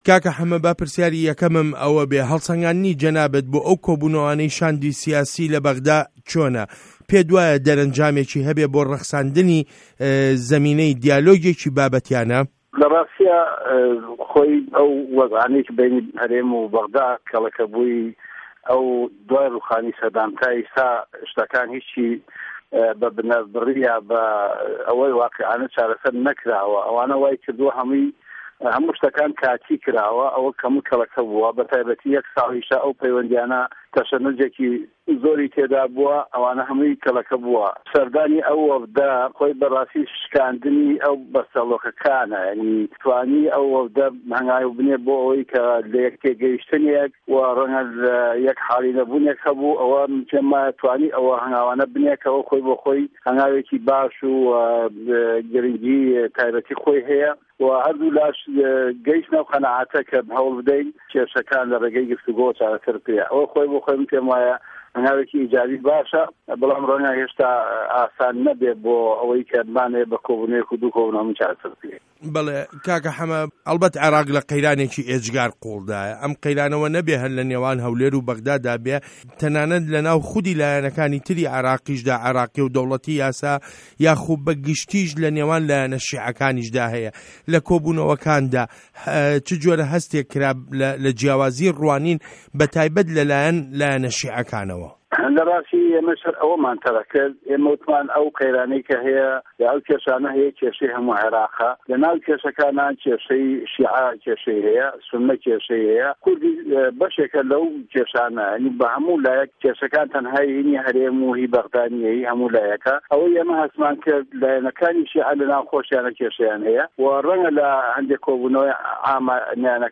وتووێژ له‌گه‌ڵ موحه‌مه‌دی حاجی مه‌حمود